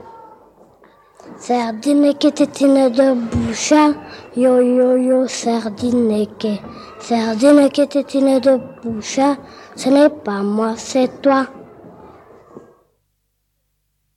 Genre : chant
Type : chanson à poter
Interprète(s) : Anonyme (femme)
Support : bande magnétique
"Comptine." (Note du collecteur)